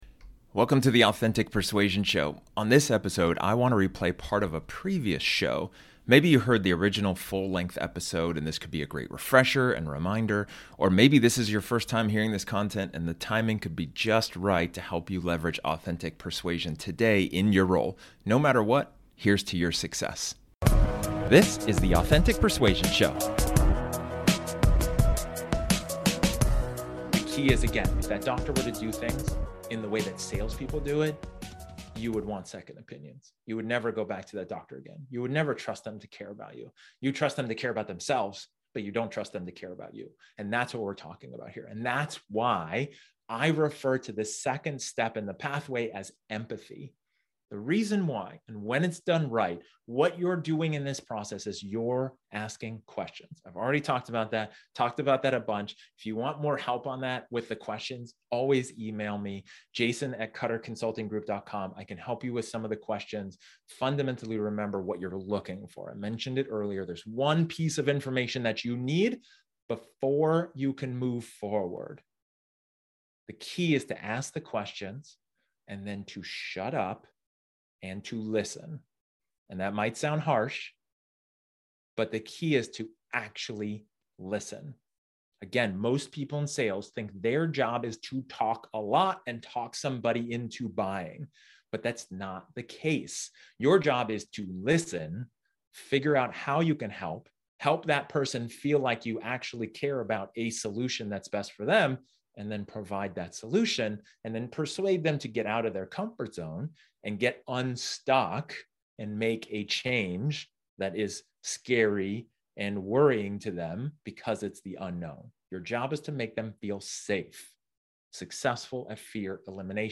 This episode is an excerpt from one of my training sessions where I talk about effective empathy.